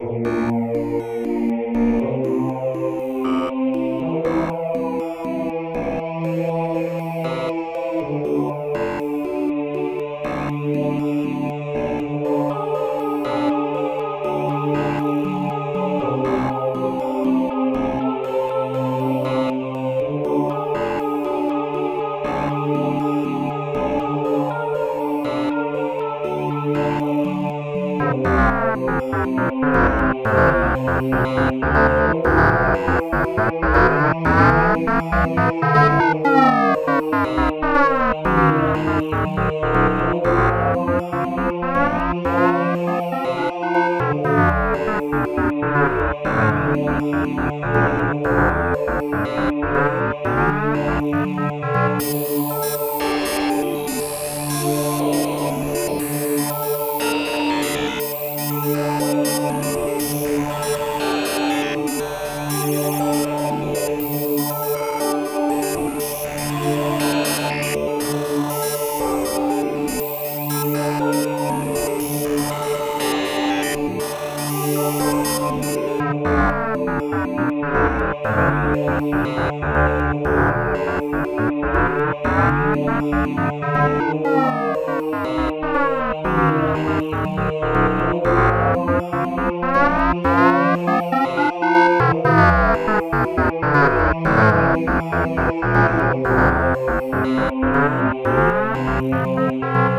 More noise music.
ANYWAYS, it's pretty funky "techy" sounding battle theme.
Edited the mixing so some parts aren't quite so loud.